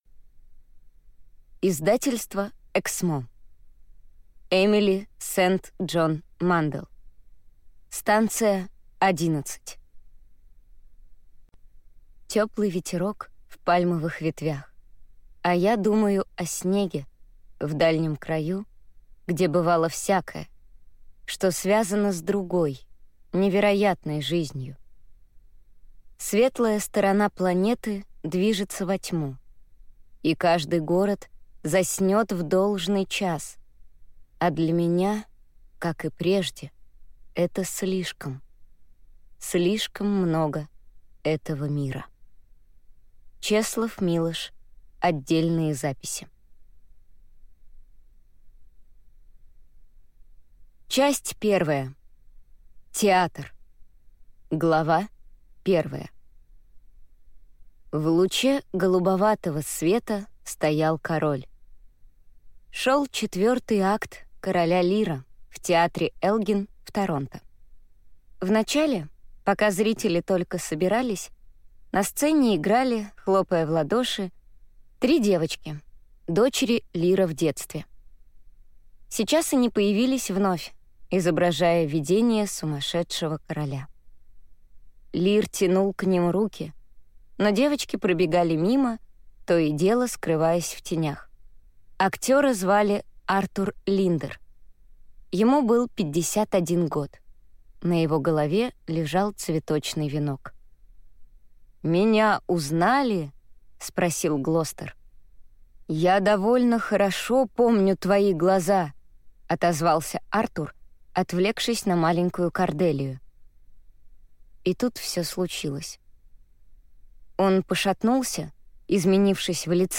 Аудиокнига Станция Одиннадцать | Библиотека аудиокниг
Прослушать и бесплатно скачать фрагмент аудиокниги